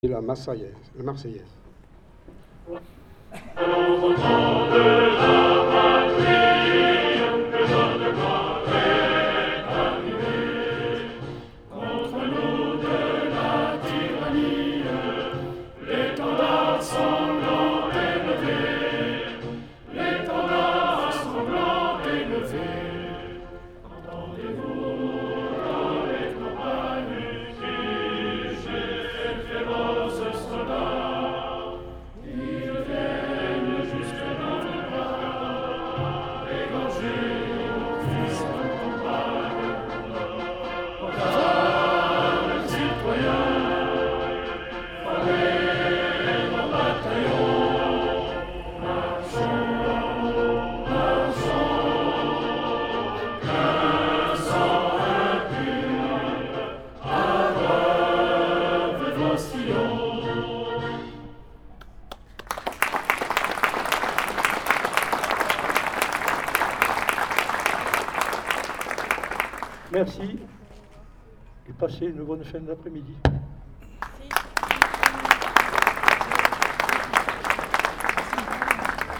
Hommage à Samuel Paty - Dax 16/10/2021
A l'issue de ce moment très émouvant, une minute de silence a ensuite été respectée avant que l'assistance n'entame en choeur une vibrante marseillaise.